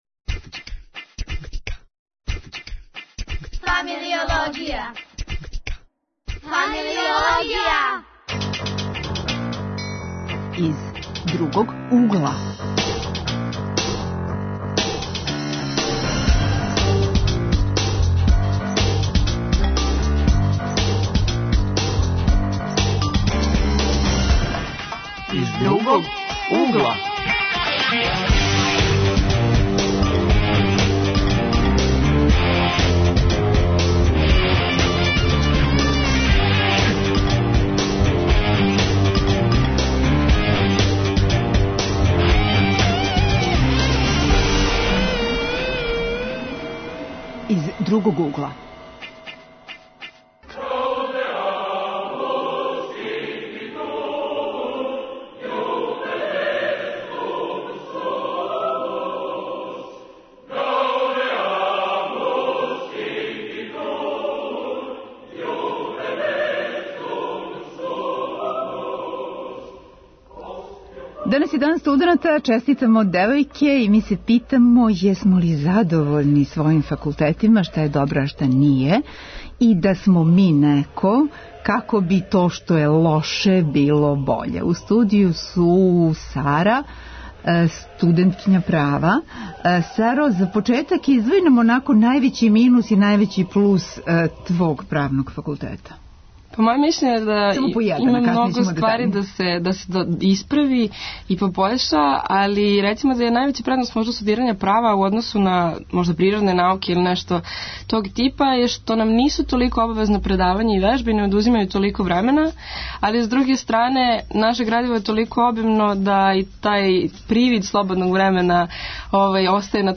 Поводом Дана студената, ми смо у знаку студирања из разноразних других углова. Гости - студенти са различитих Универзитета, који ће истаћи своје добре и лоше стране Факултета који су изабрали